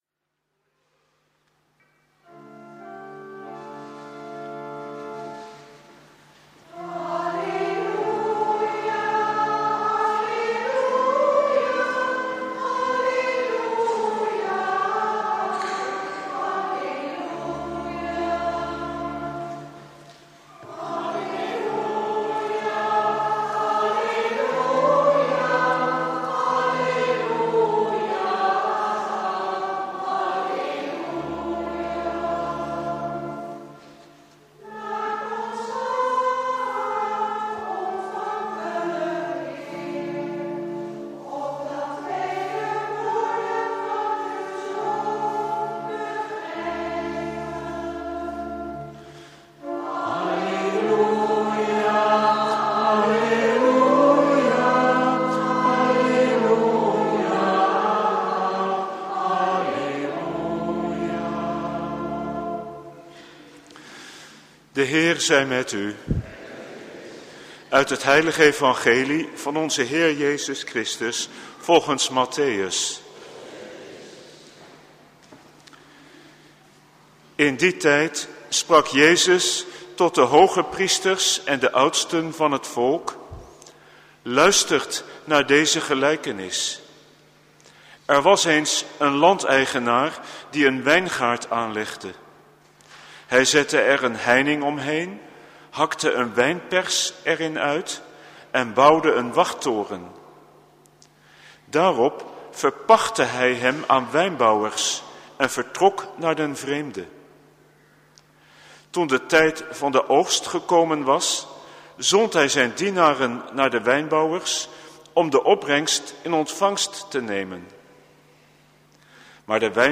Homilie mgr. Van den Hende
Preek op de zevenentwintigste zondag door het jaar A Preek beluisteren van Bisschop Van den Hende (MP3) (de homilie begint op 4:30)